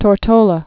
(tôr-tōlə)